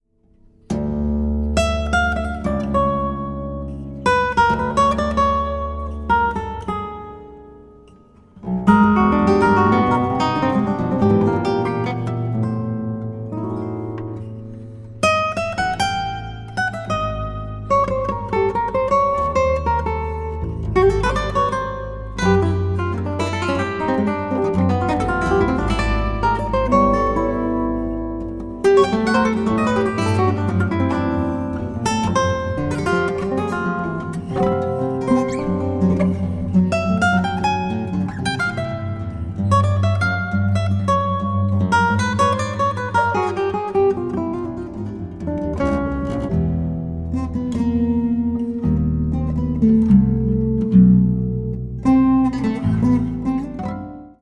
16-string classical guitar